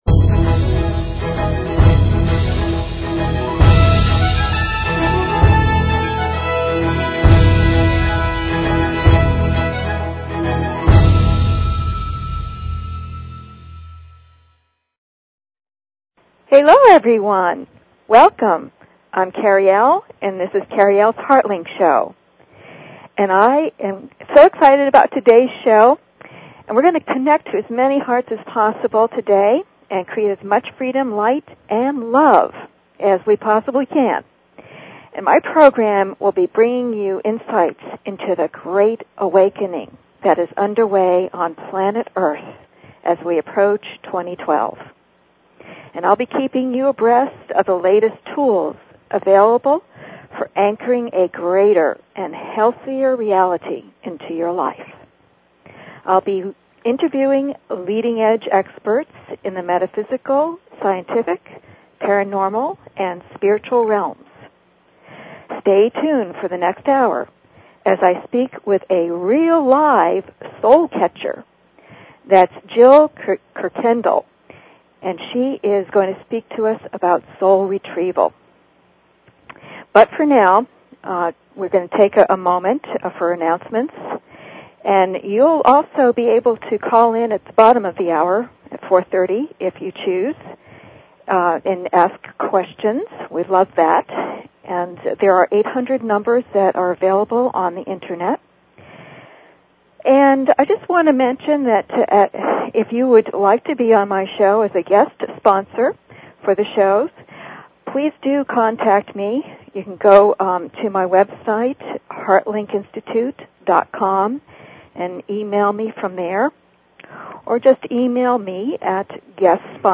Talk Show Episode
Please consider subscribing to this talk show.